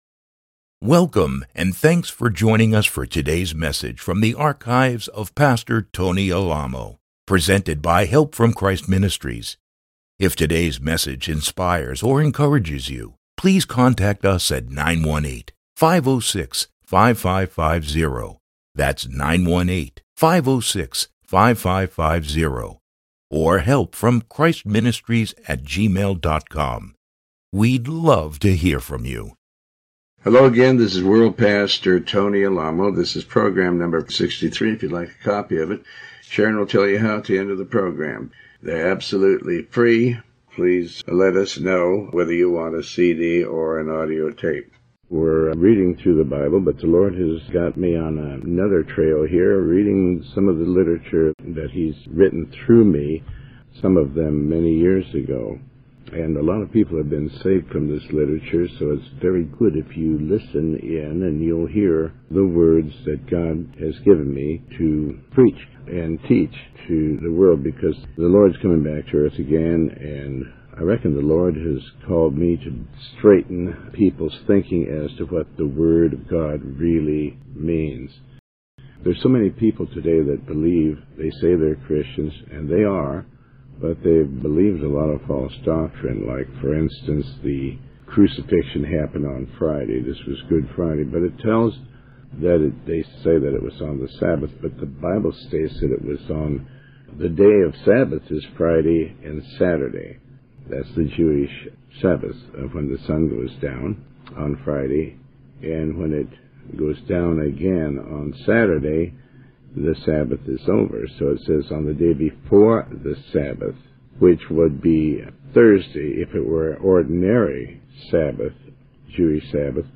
Sermon 63